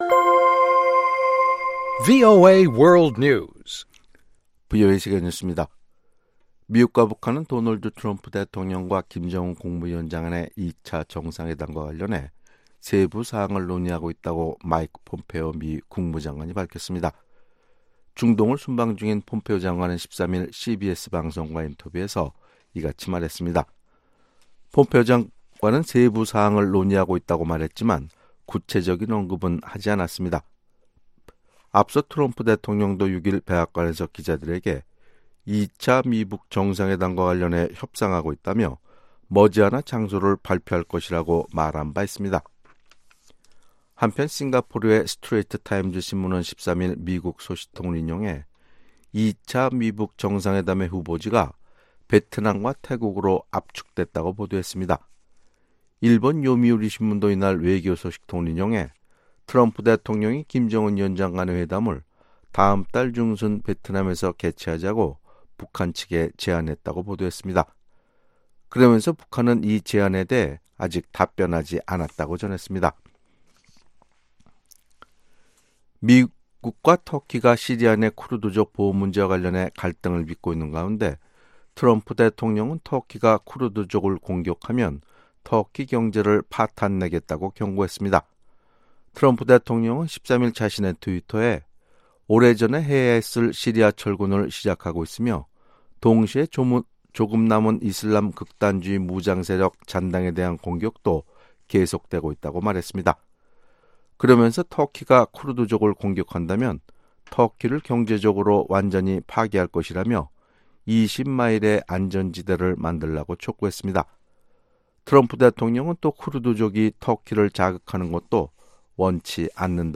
VOA 한국어 아침 뉴스 프로그램 '워싱턴 뉴스 광장' 2019년 1월 15일 방송입니다. 마이크 폼페오 미 국무장관은 현재 2차 미-북 정상회담 개최를 위한 세부사항이 논의되고 있다고 밝혔습니다. 미국의 전문가들은 2차 미-북 회담 개최 전에 비핵화 정의부터 명확히 해야 진전이 있을 것으로 전망했습니다.